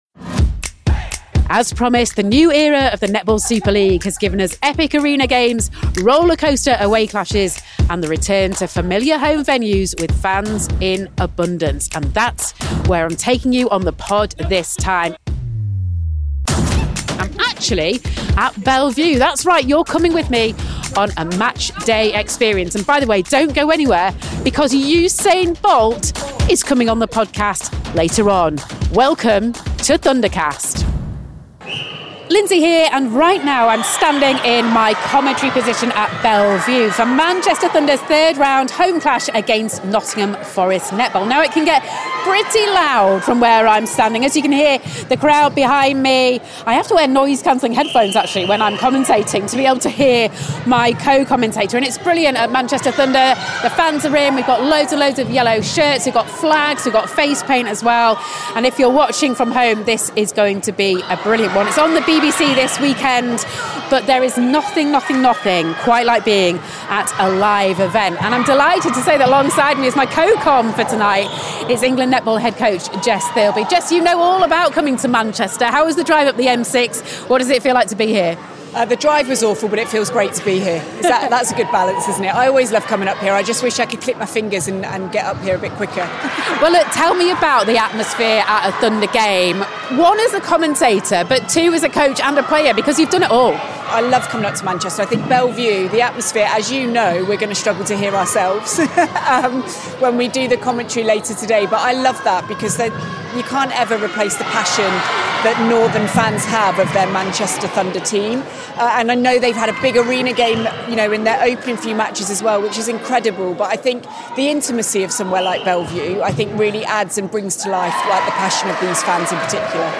FANalysis goes out into the crowd to soak up the atmosphere on game day and well as delving into the tactics on court as Thunder chalk up a decisive win over Nottingham Forest Netball.